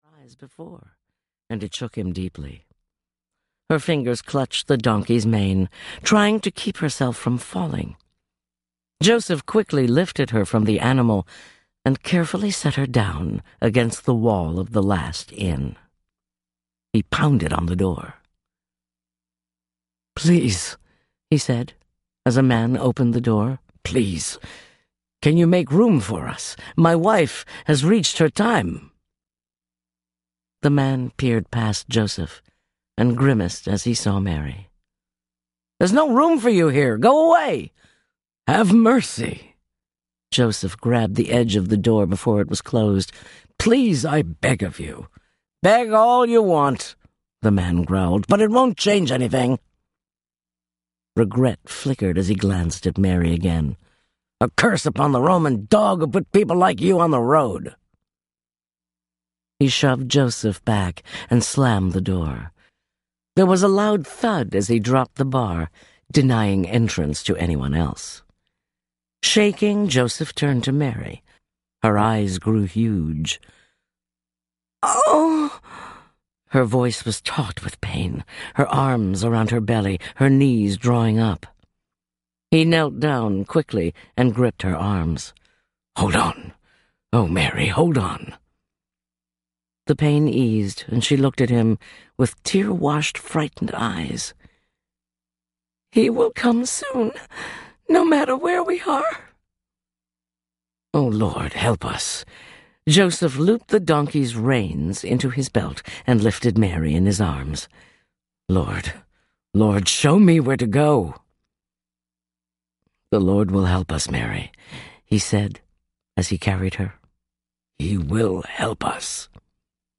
Unafraid (Lineage of Grace, Book #5) Audiobook
Narrator